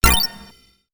UI_SFX_Pack_61_25.wav